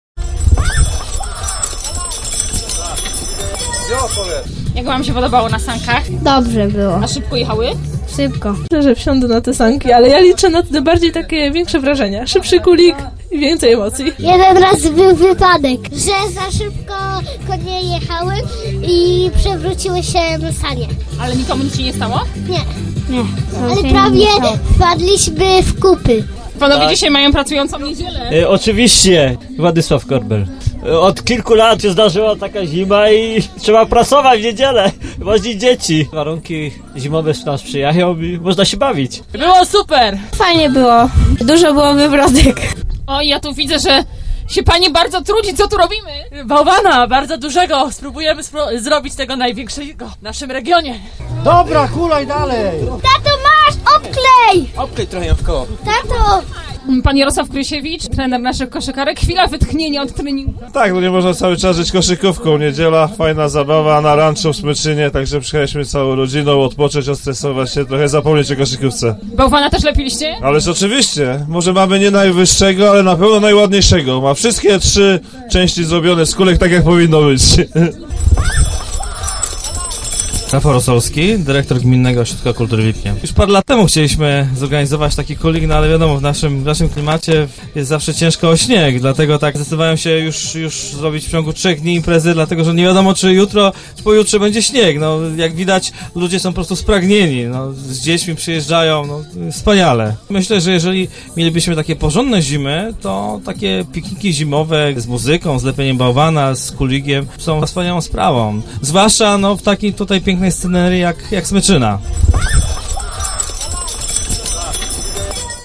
22 lutego 2009, Ranczo Smyczyna